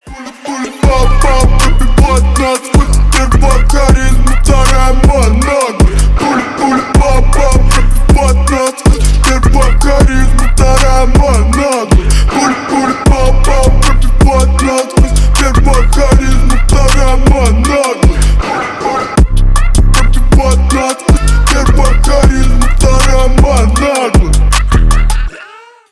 басы , громкие
рэп , хип-хоп